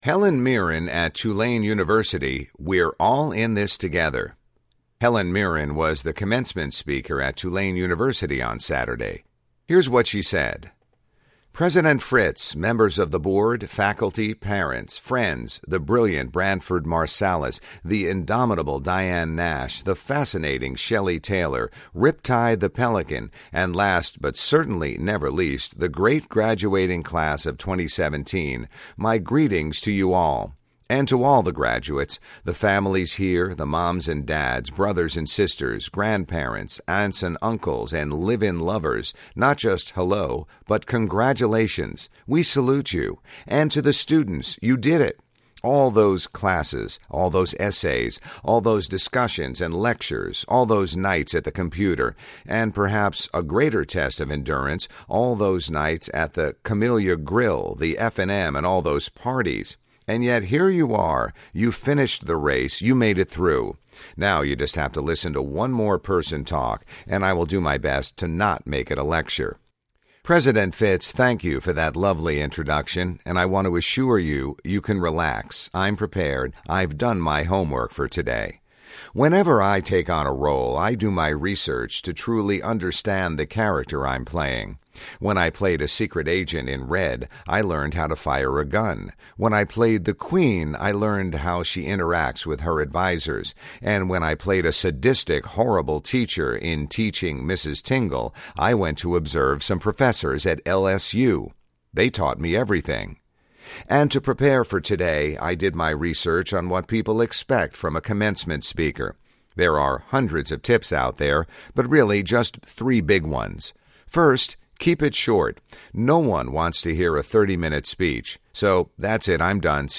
Dame Helen Mirren was the commencement speaker at Tulane University on Saturday.